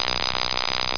1 channel
Electric.mp3